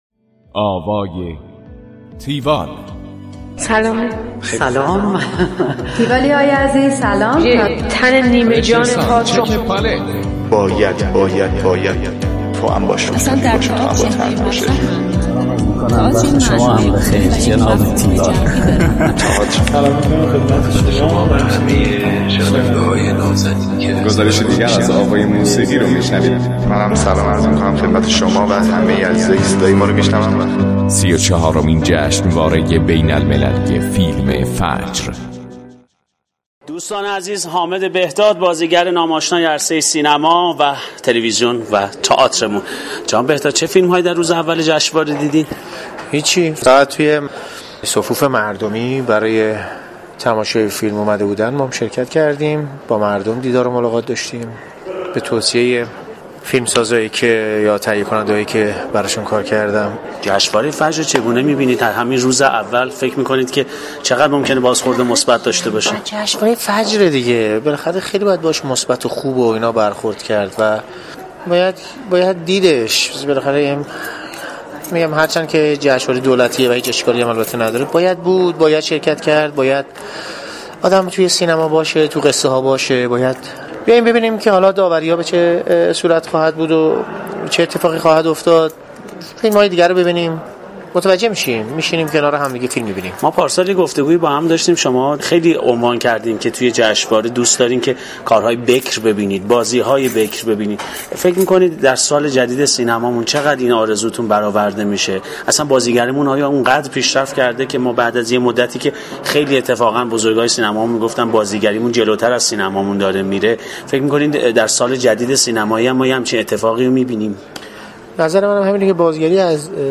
گفتگوی تیوال با حامد بهداد
tiwall-interview-hamedbehdad.mp3